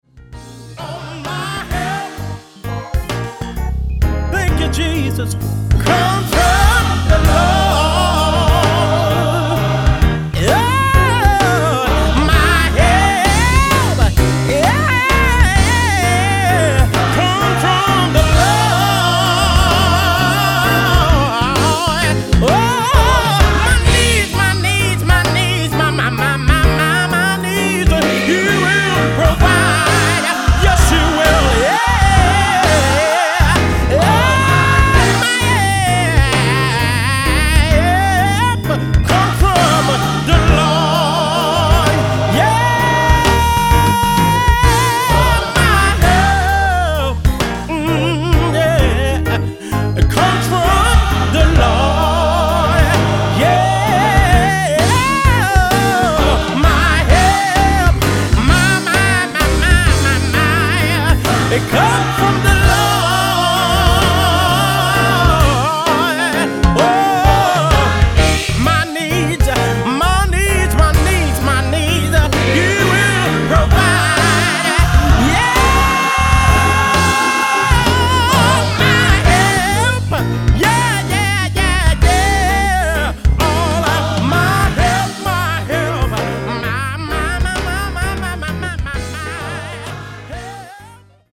CHOIR